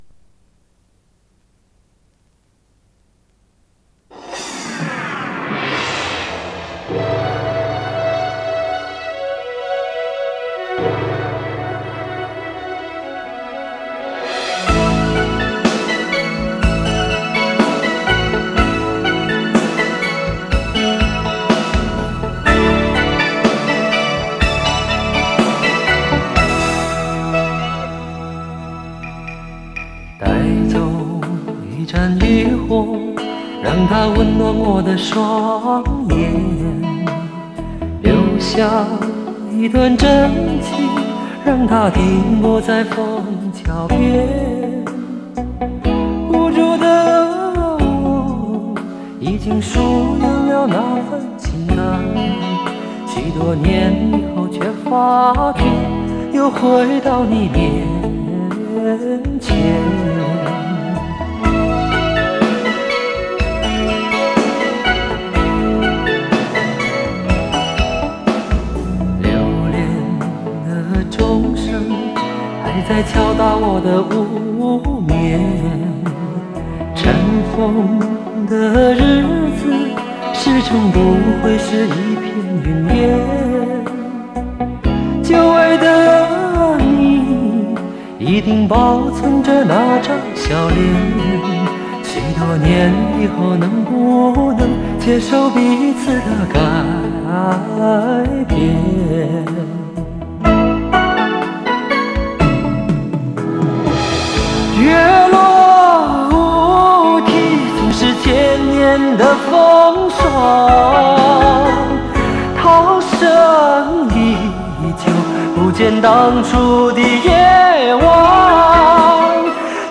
磁带数字化：2022-09-12